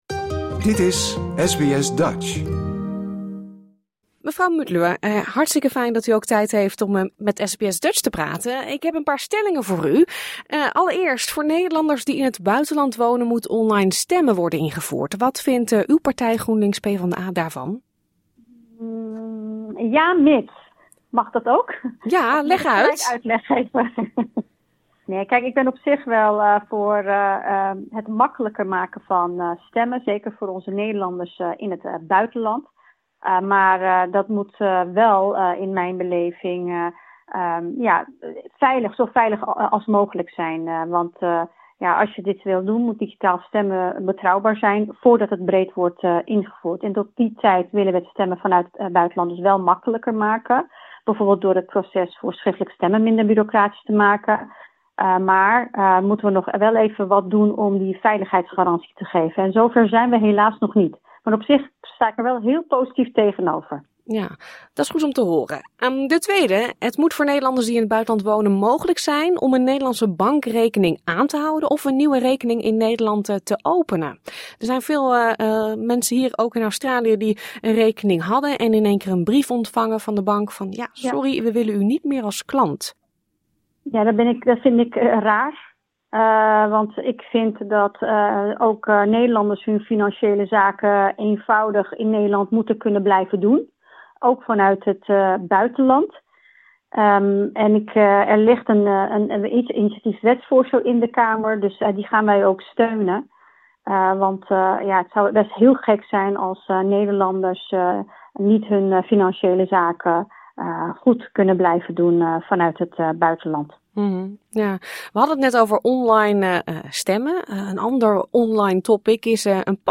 SBS Dutch sprak met vier partijen* en legde iedere kandidaat dezelfde stellingen en vragen voor.